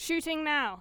Voice Lines / Barklines Combat VA